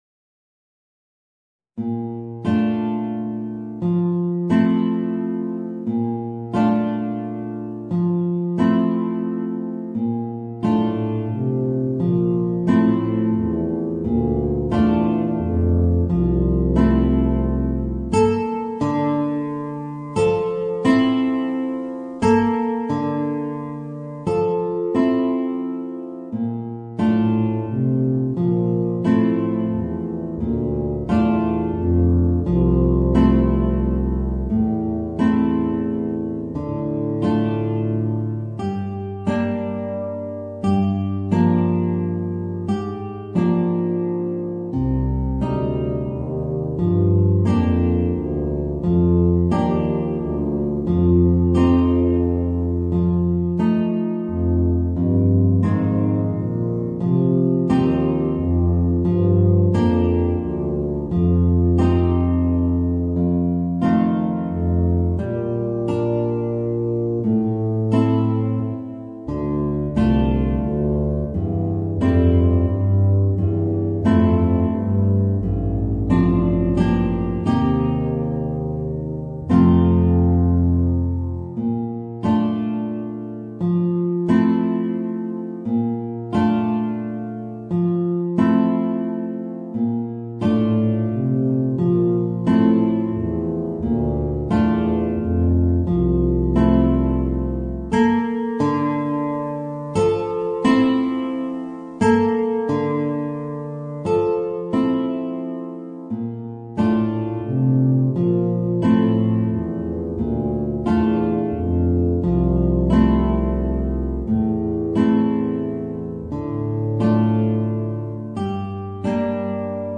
Voicing: Bb Bass and Guitar